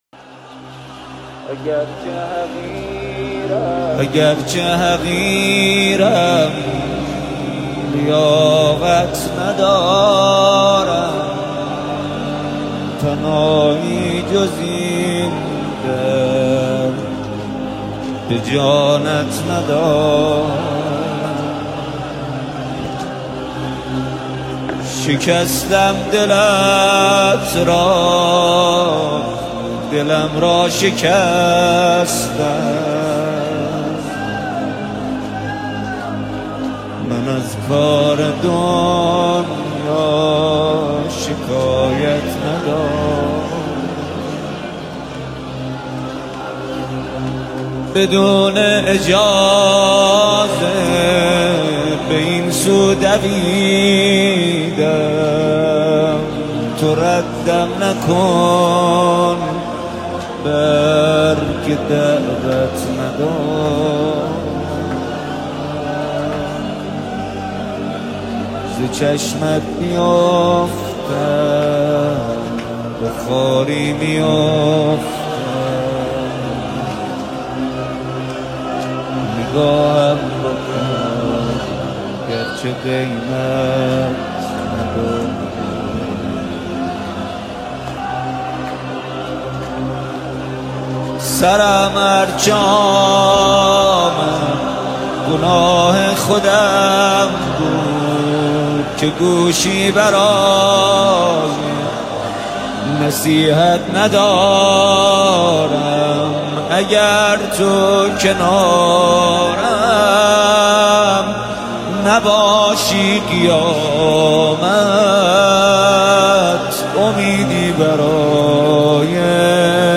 نماهنگ حزین و دلنشین